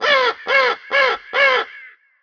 crow2.wav